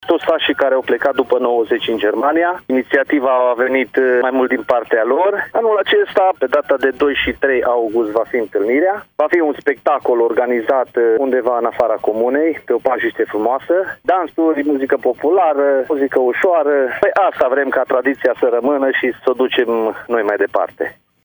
Nistor Boricean, primar Măieruș: